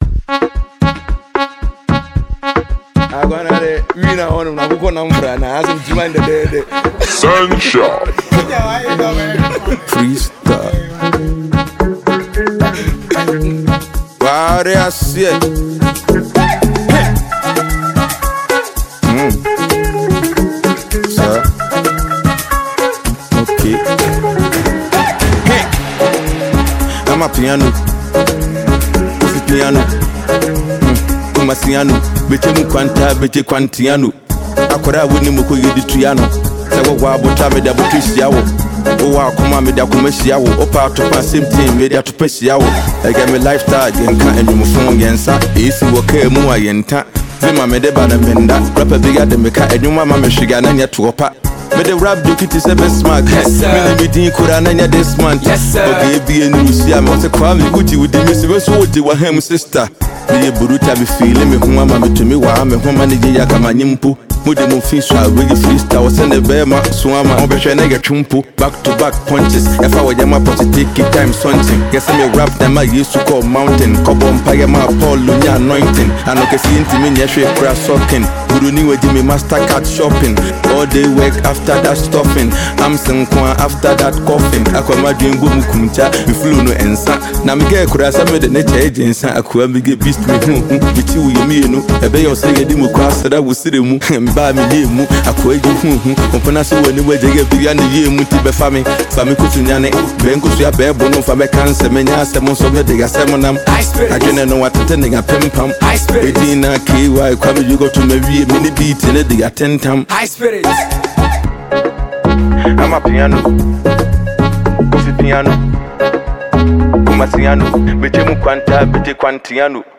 Ghanaian rapper
freestyle song